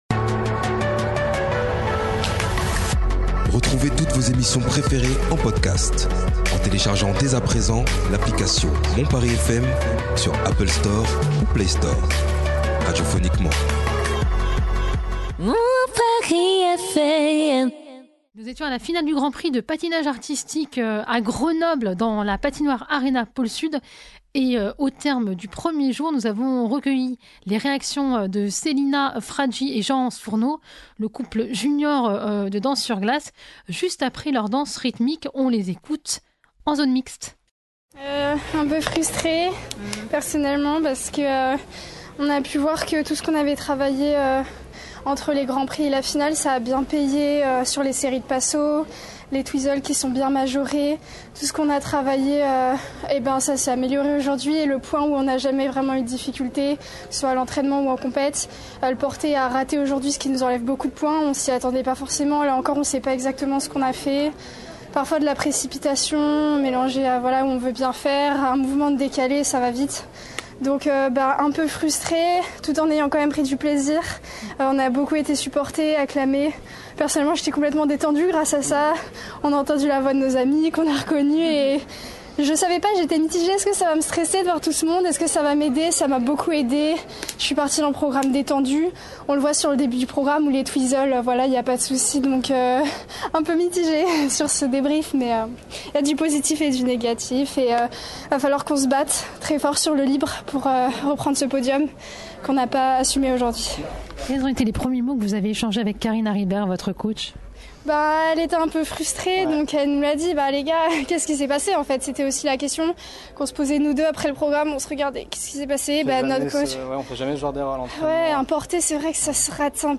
Reportage réalisé à Grenoble du 5 au 7 décembre 2024